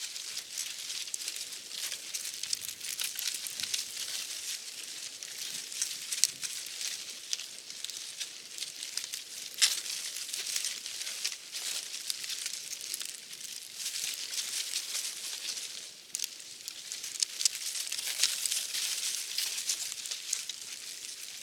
windbush_4.ogg